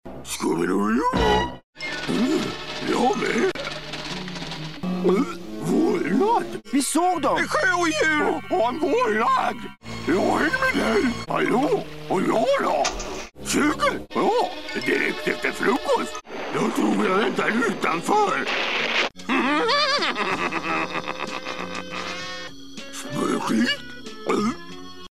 Jag såg att jag faktiskt hade lagt upp ljudklipp på Scooby-Doos olika röster för ett par år sedan, och de filerna fanns kvar på servern.